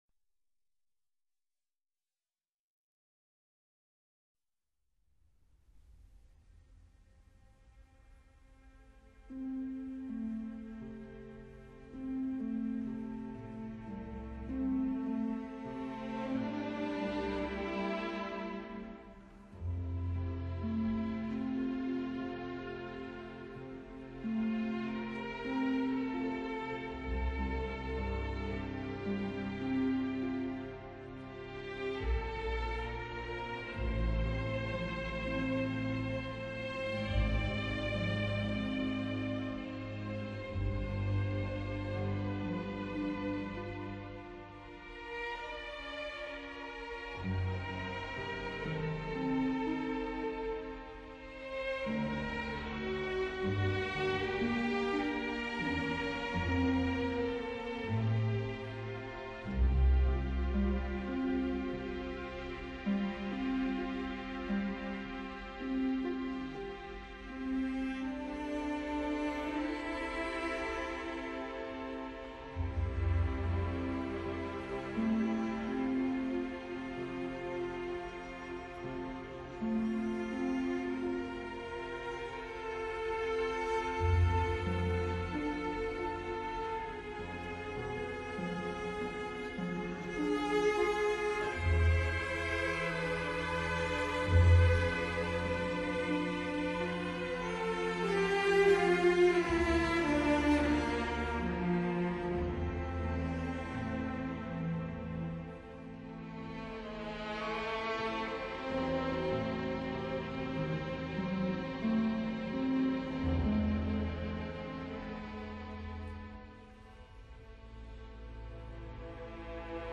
升C小调